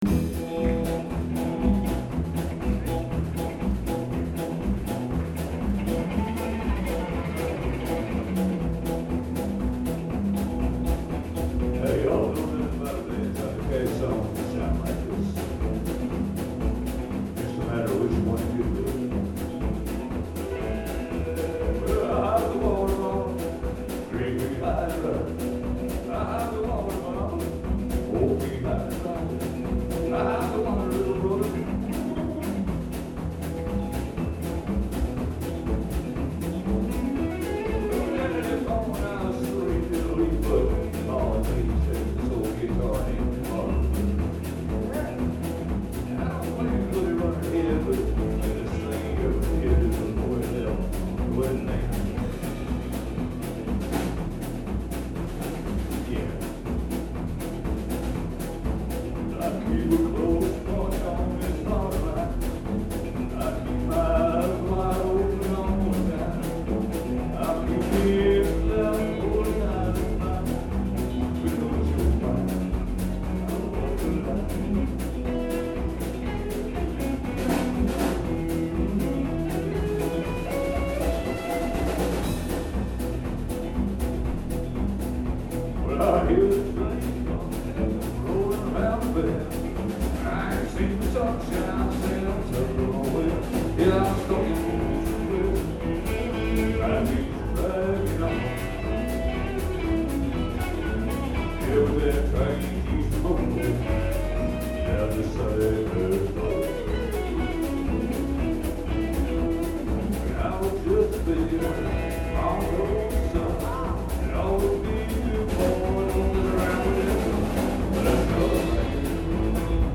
Bad Motor Scooter Band performs at Jollie's Lounge, April 11, 2014